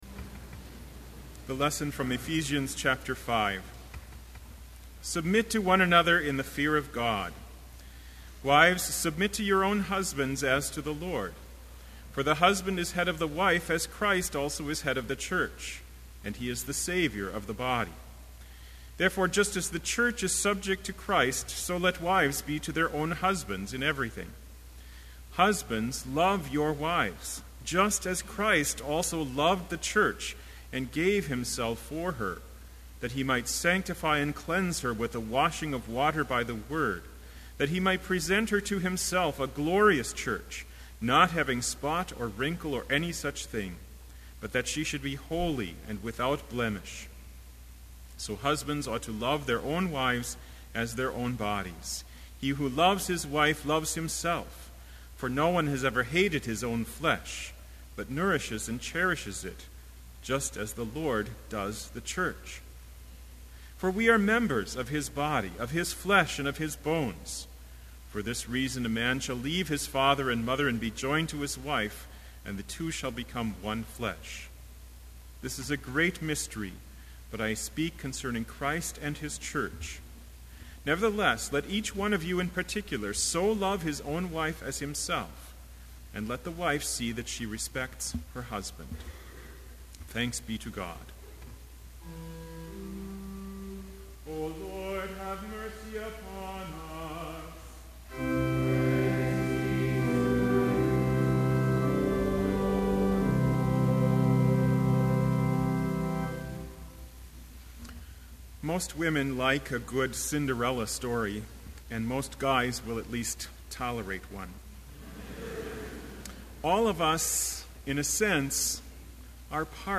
Sermon Only
This Chapel Service was held in Trinity Chapel at Bethany Lutheran College on Wednesday, January 18, 2012, at 10 a.m. Page and hymn numbers are from the Evangelical Lutheran Hymnary.